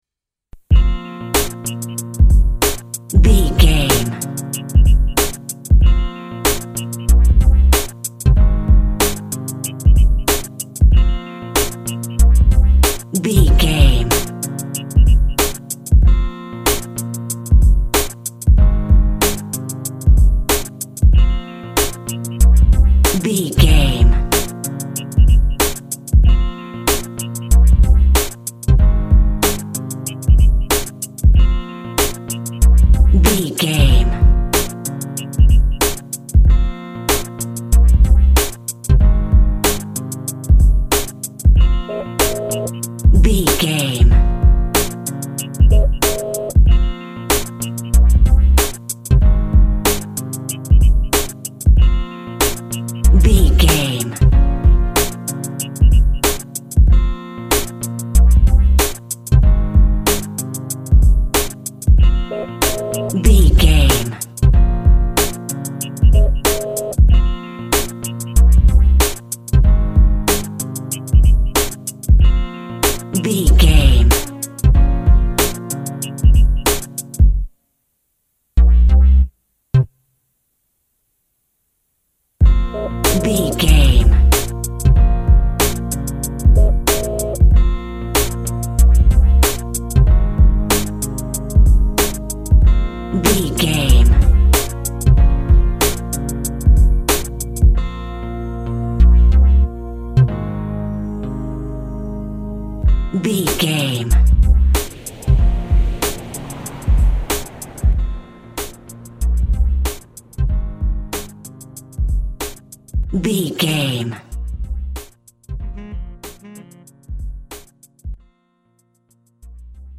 Aeolian/Minor
B♭
hip hop
synth lead
synth bass
hip hop synths